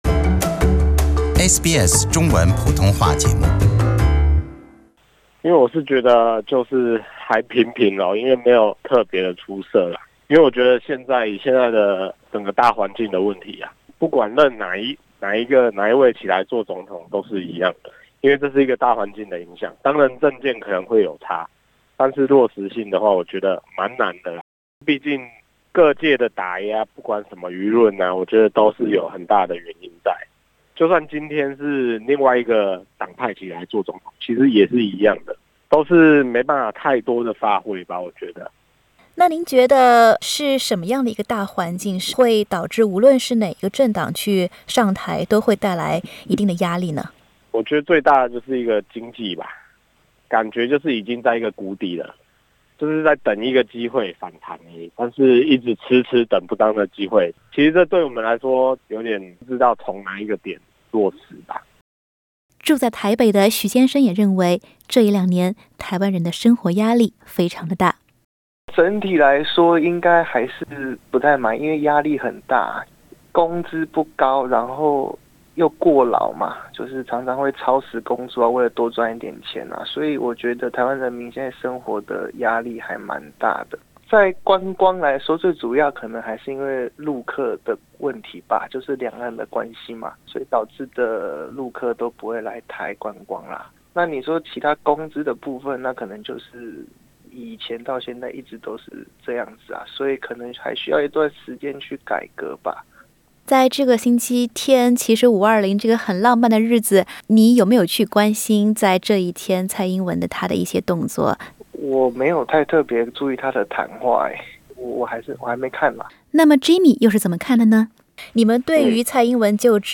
本节目为嘉宾观点，不代表本台立场。